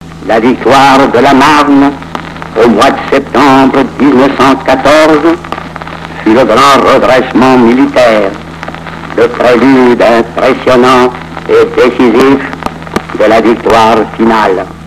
Discours pour la guerre des tranchées Gaston Doumergue (1863-1937)